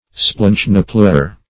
Search Result for " splanchnopleure" : The Collaborative International Dictionary of English v.0.48: Splanchnopleure \Splanch"no*pleure\, n. [Gr.
splanchnopleure.mp3